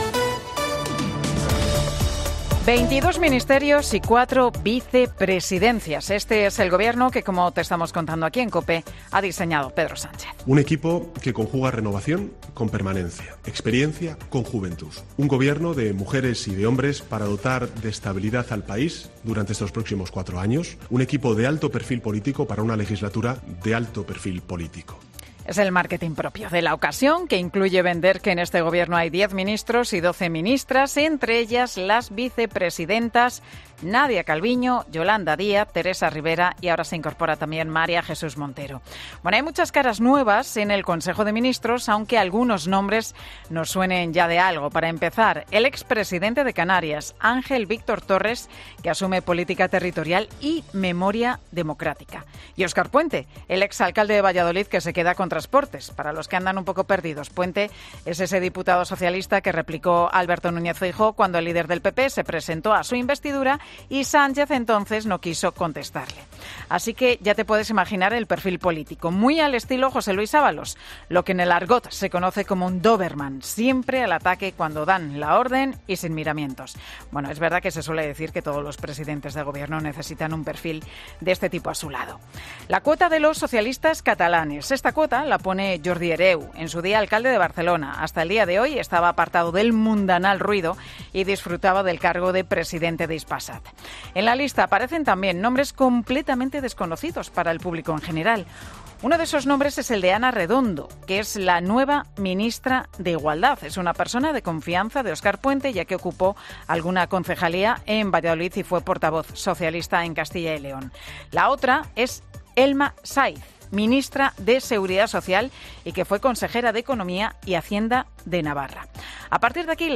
Monólogo de Pilar García Muñiz